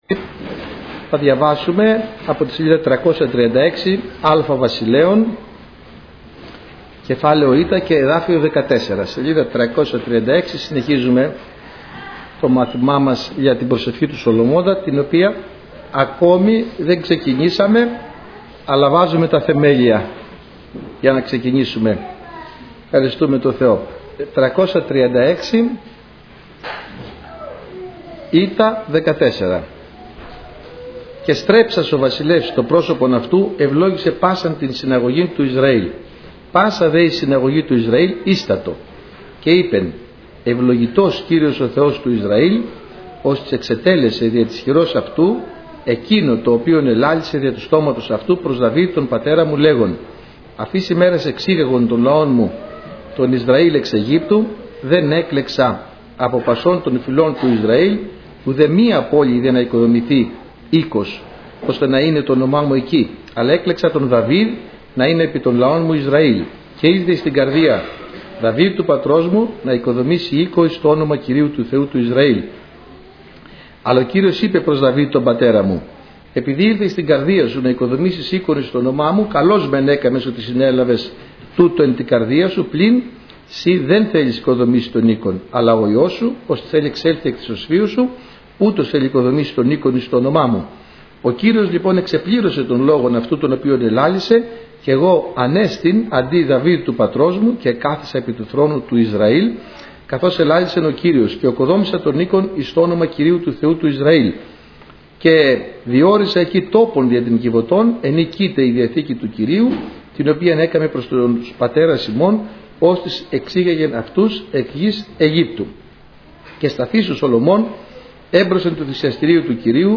Σειρά: Μαθήματα